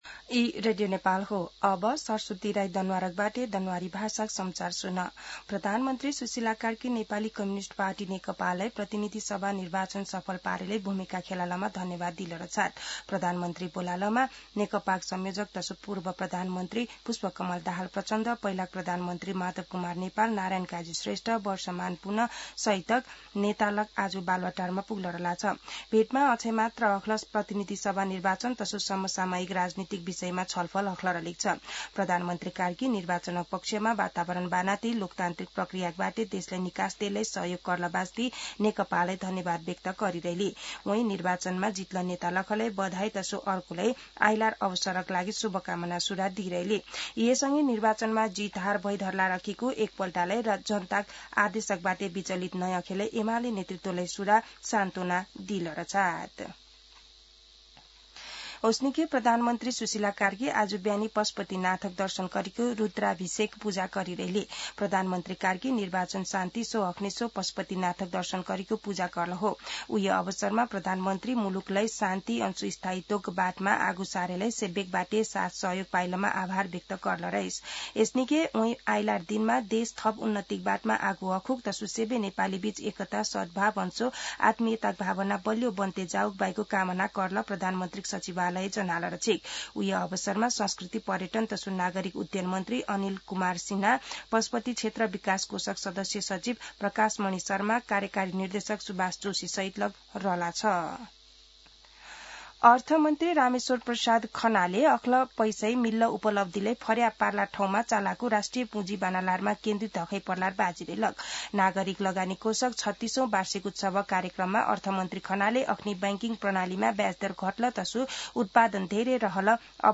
दनुवार भाषामा समाचार : ४ चैत , २०८२
Danuwar-News-04.mp3